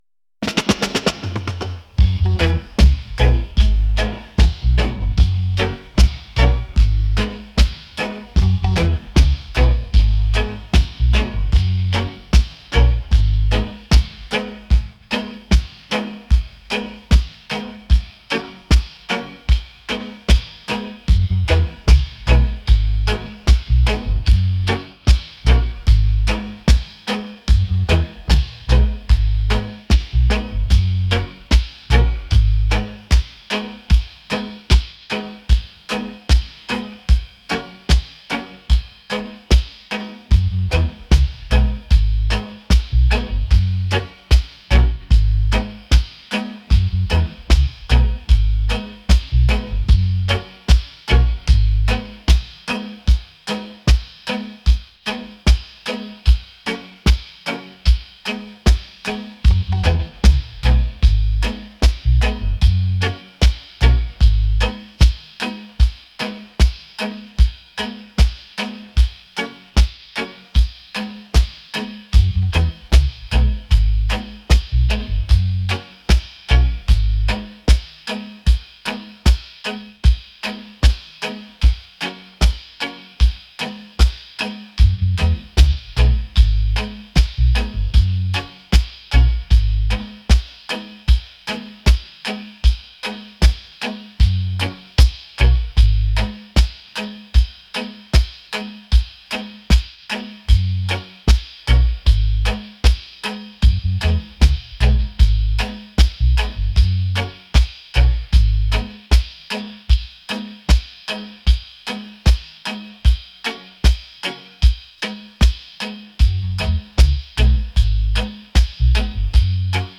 reggae | romantic